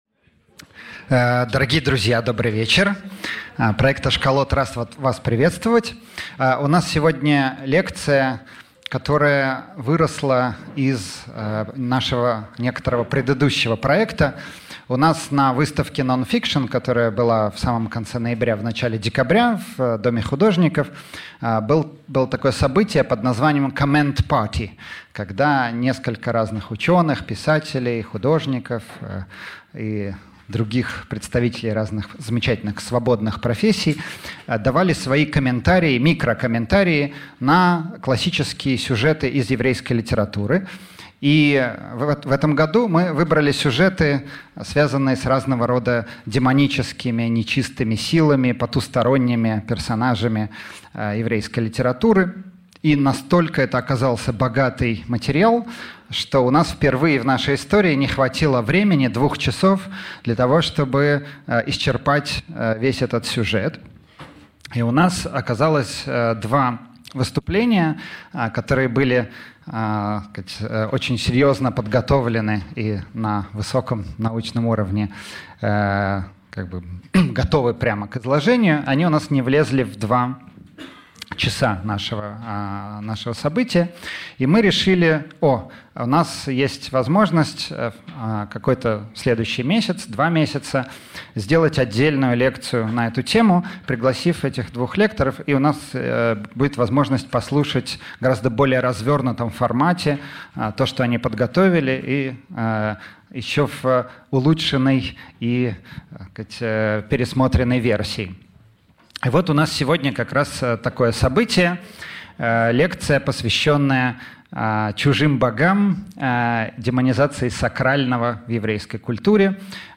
Аудиокнига Демонизация сакрального в еврейской культуре | Библиотека аудиокниг